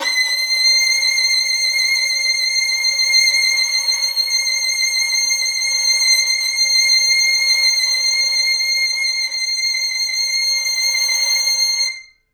violin
C7.wav